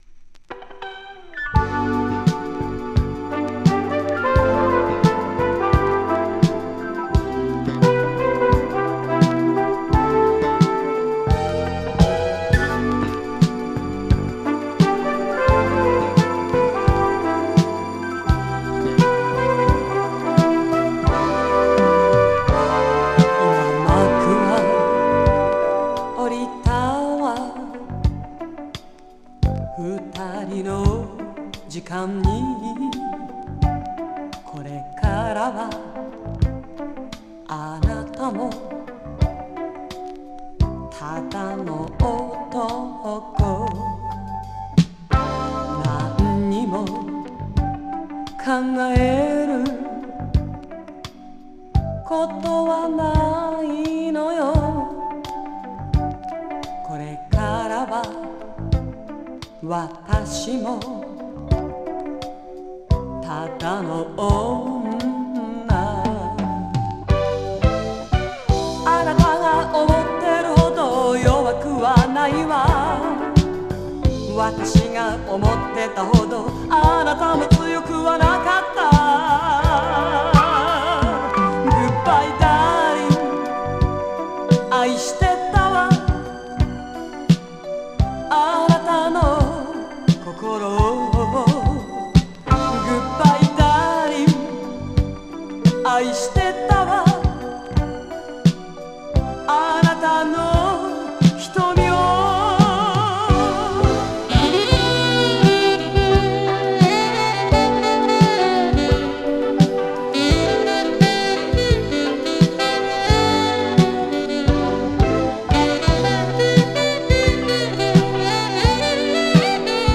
宝塚星組トップスター時代の歌唱力が存分に発揮された珠玉の一枚で、ミュージカル曲のカバーも収録。和製フリーソウル